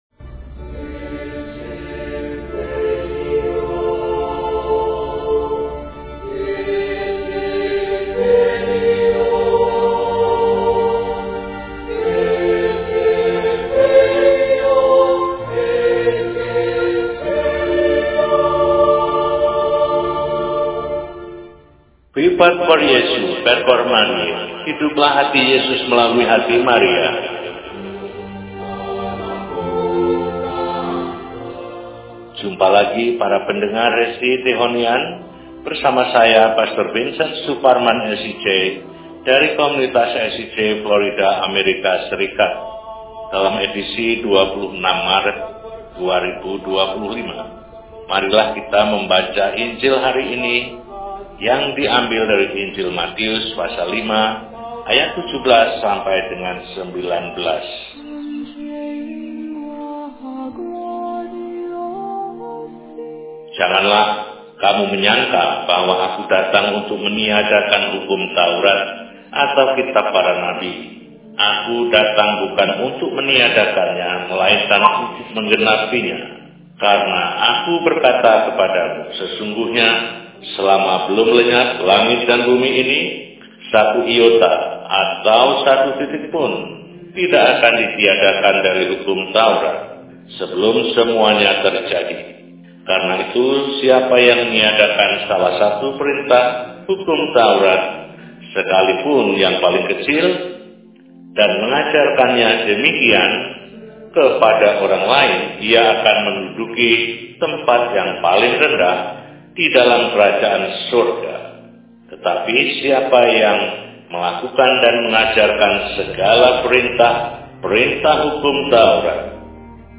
Rabu, 26 Maret 2025 – Hari Biasa Pekan III Prapaskah – RESI (Renungan Singkat) DEHONIAN